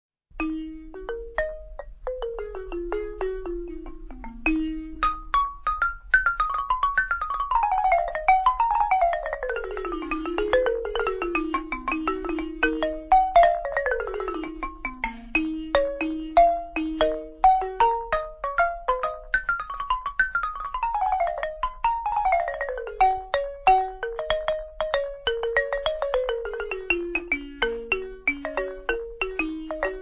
Solo de balafon